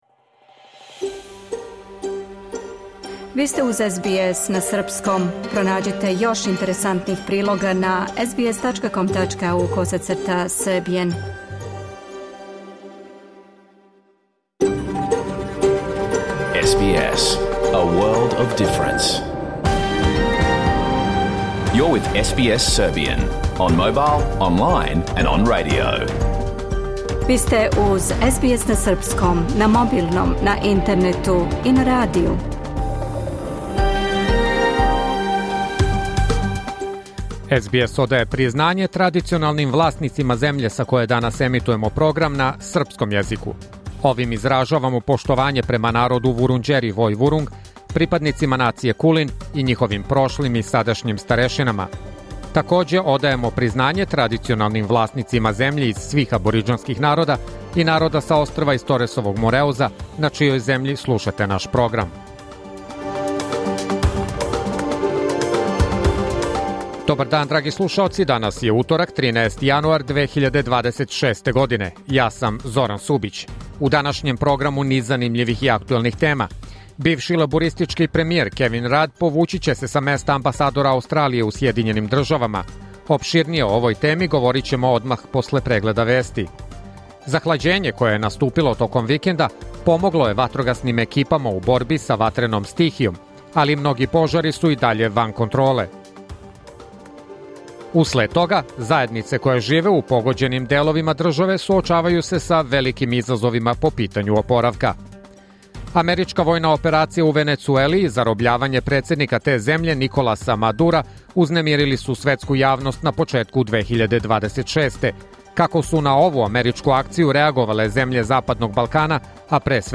Програм емитован уживо 13. јануара 2026. године